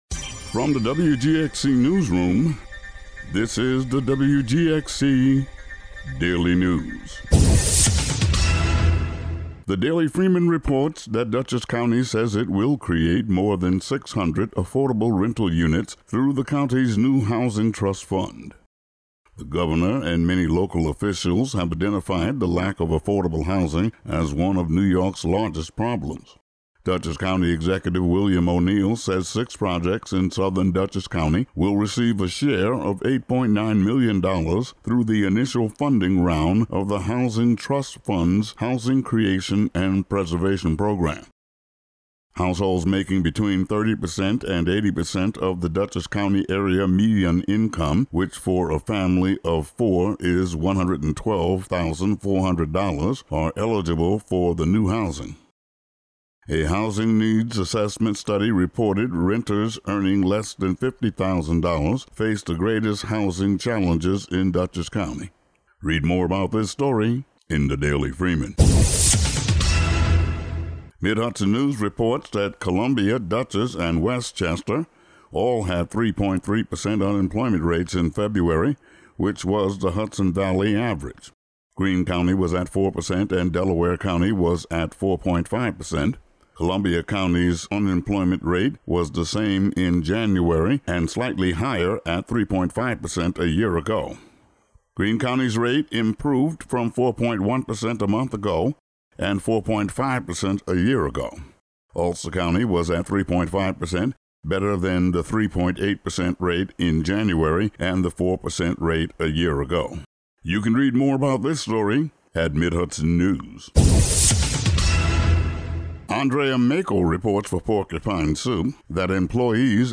Today's daily news audio update.